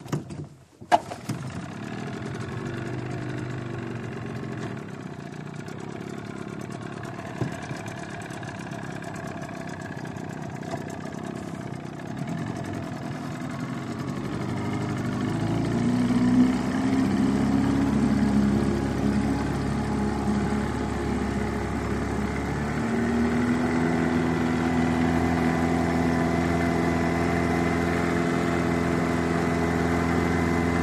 20 hp Johnson Boat Away Loop Start, Drive